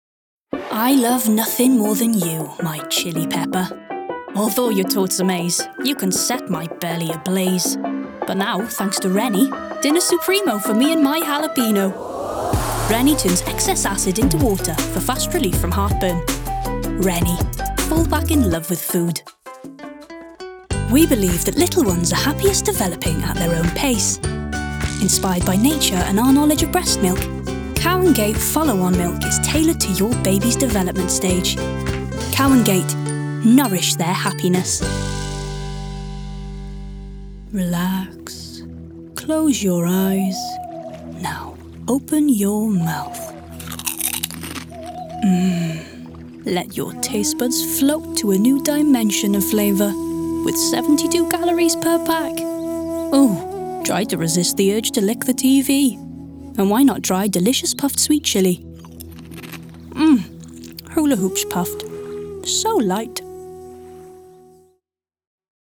Commercials
Embrace the warmth and energy of her voice—your audience will thank you for it!
Female
Geordie
Northern
Bright
Friendly
Voice Next Door
Youthful